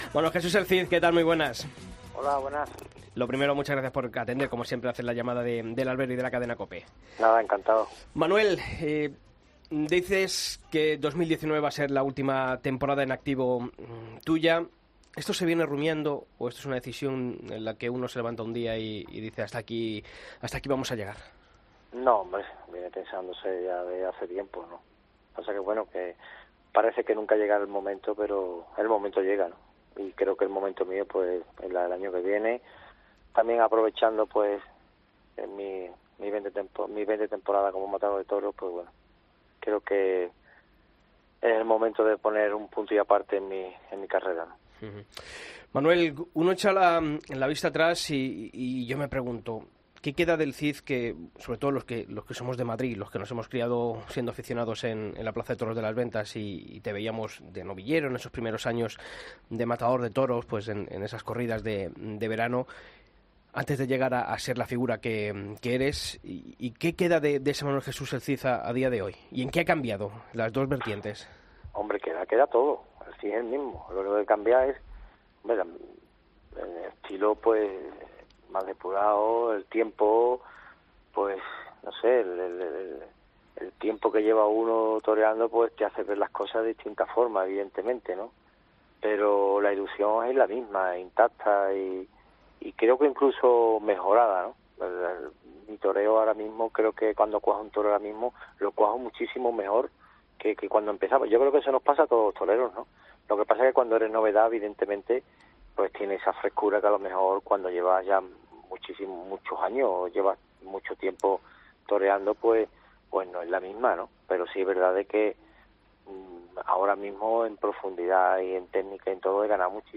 Durante la entrevista también hubo especial atención al hierro que ha marcado la carrera de El Cid .